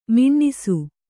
♪ miṇṇisu